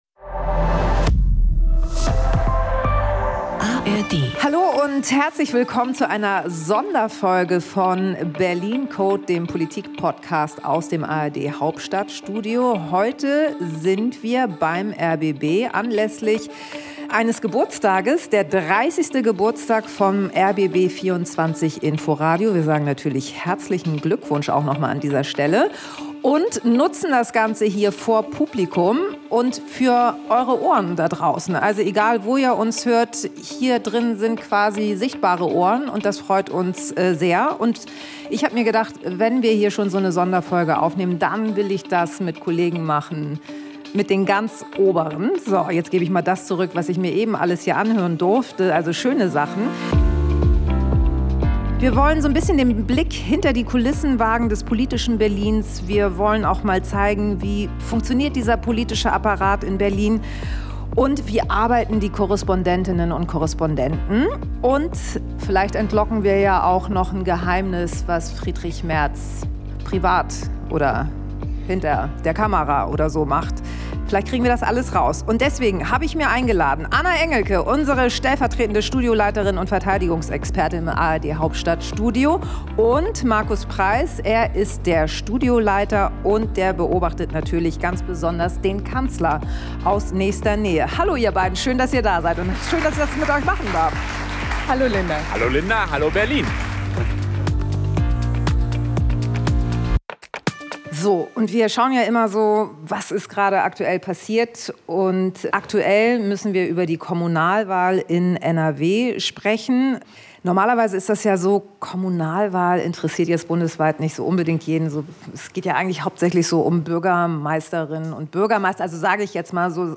Aber nicht nur die beiden sind zu Gast: Diese Episode vom "Berlin Code" ist ein Live-Mitschnitt vor Publikum vom 15. September 2025 aus der Dachlounge des rbb hoch über Berlin. Anlass ist Feier zu 30 Jahre rbb24 Inforadio.